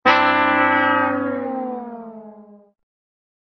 Orchestra Fail Sound Effect Free Download
Orchestra Fail